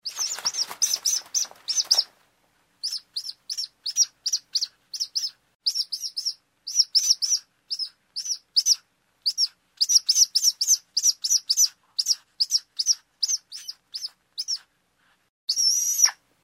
Звук: одинокий крик ястреба в небесном полете